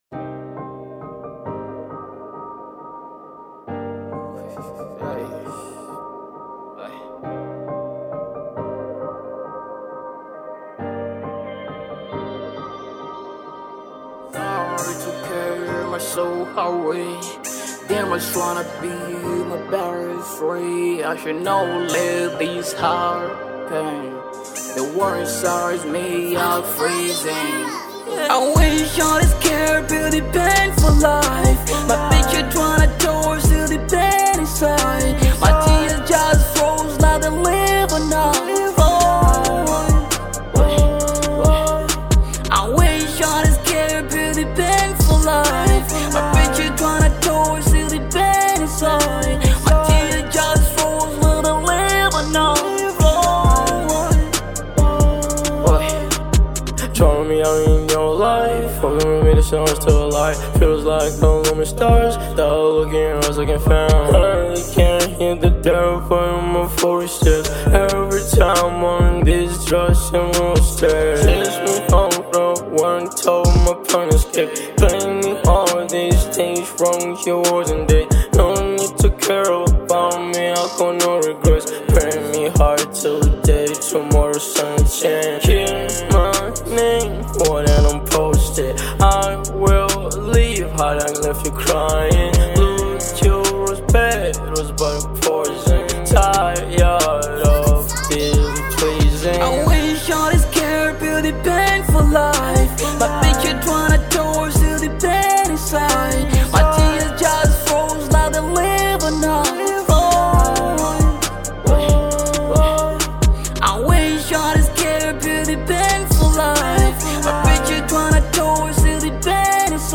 Genre : Hiphop/Trap